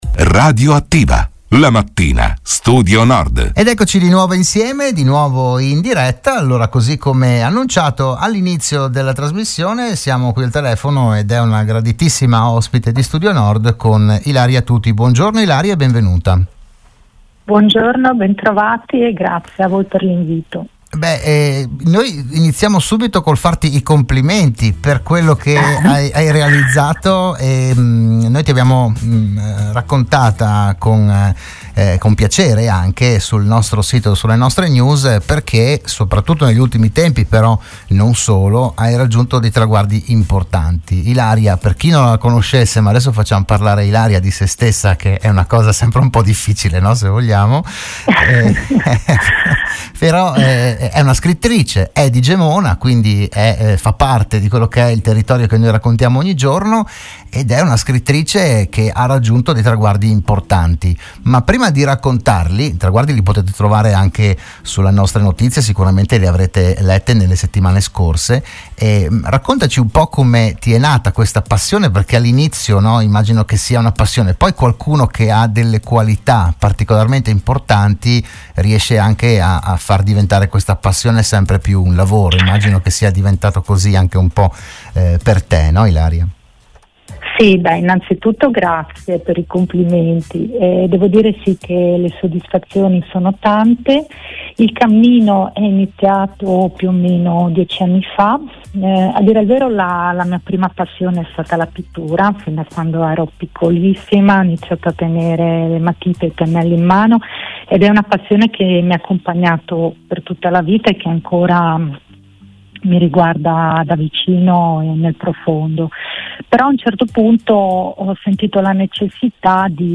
La scrittrice è stata ospite oggi telefonicamente di “RadioAttiva“, la trasmissione di Radio Studio Nord